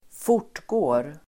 Uttal: [²f'or_t:gå:r]